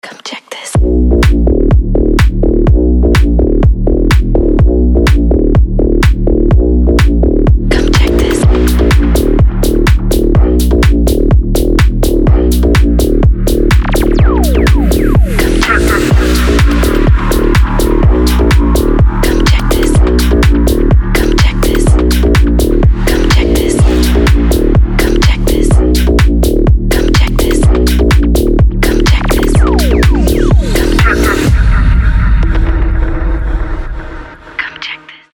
• Качество: 320, Stereo
жесткие
EDM
мощные басы
чувственные
Bass House
качающие
Чувственный bass house